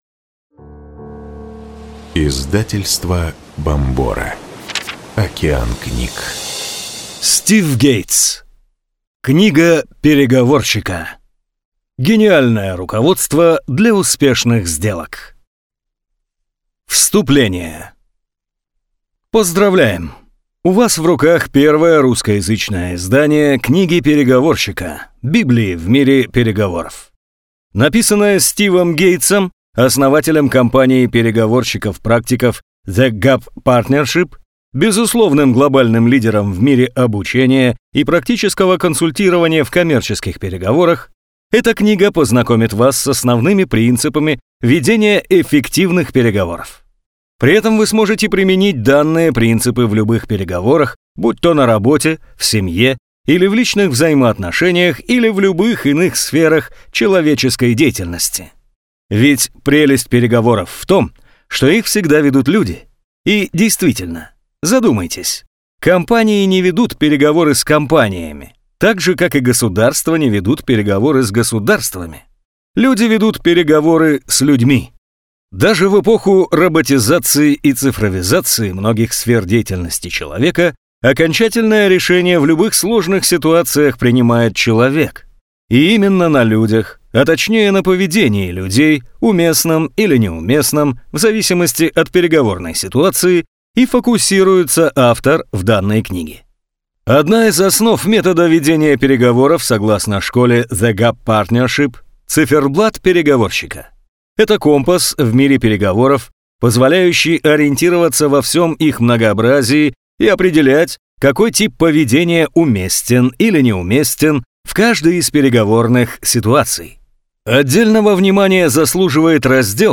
Аудиокнига Книга переговорщика. Гениальное руководство для успешных сделок | Библиотека аудиокниг